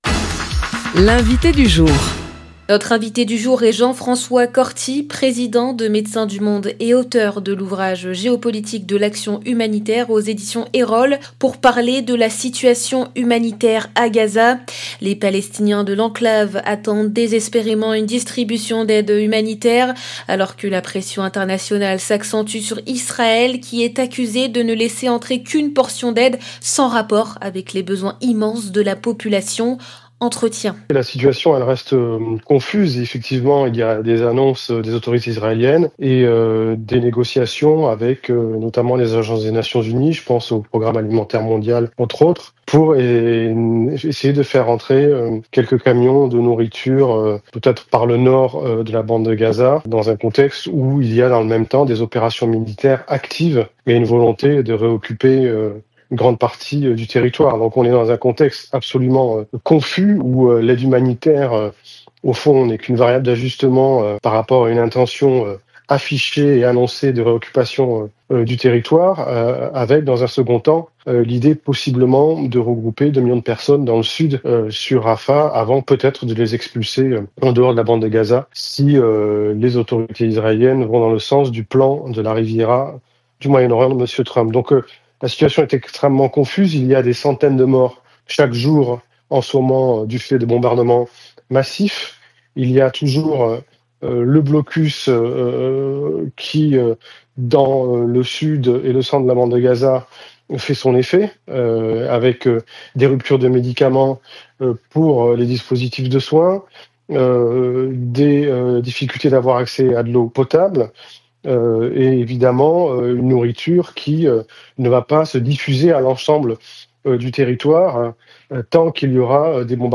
Alors que la pression internationale sur Israël augmente, ce dernier est accusé de ne laisser entrer qu'une fraction de l'aide nécessaire, loin de suffire aux besoins immenses de la population. Entretien. 0:00 9 min 13 sec